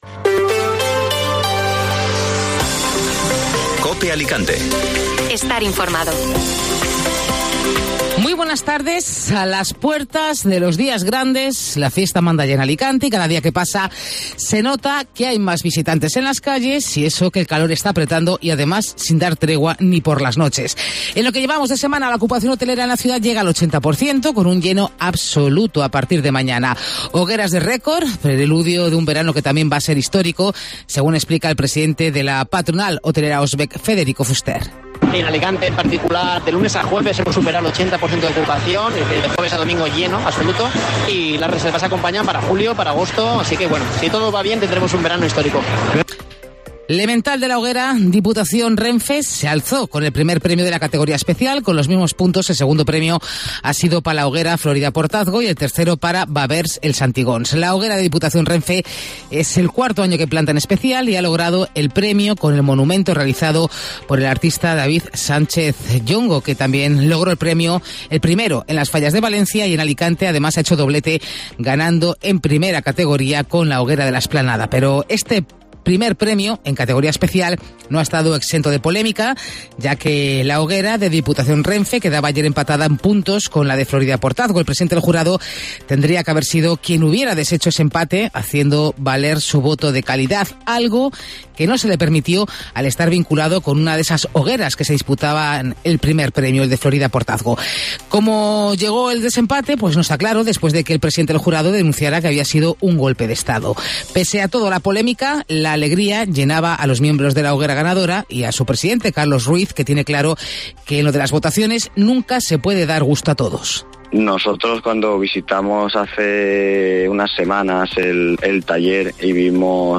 Informativo Mediodía Cope Alicante (jueves 22 de Junio)